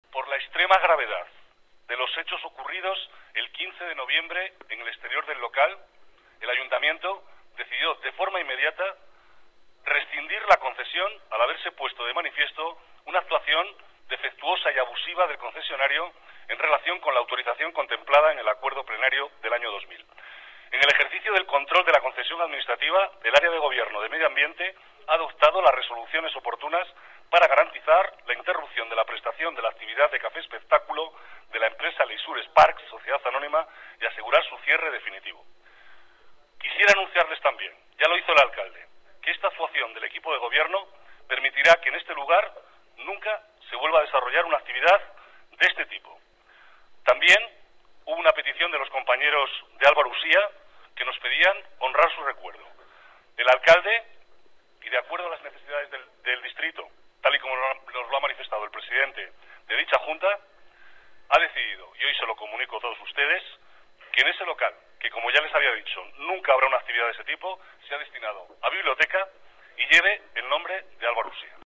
Nueva ventana:Declaraciones del vicealcalde Manuel Cobo